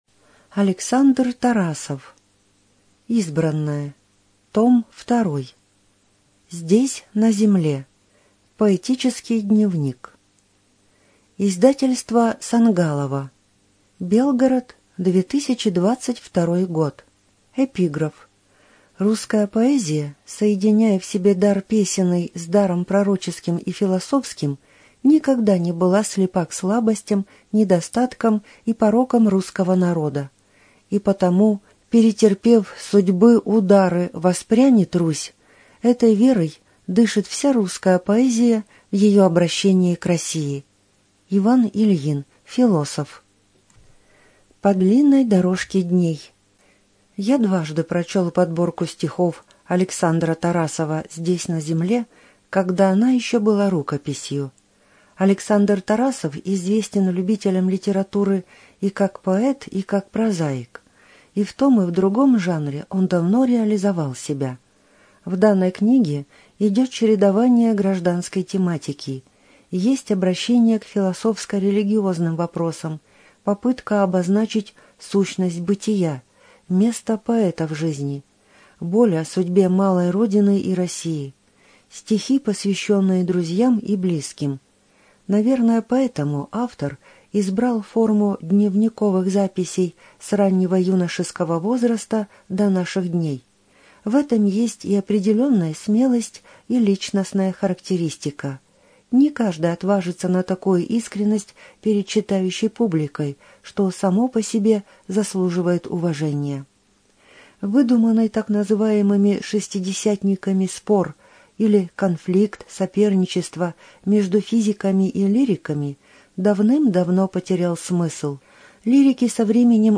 Студия звукозаписиБелгородская областная библиотека для слепых имени Василия Яковлевича Ерошенко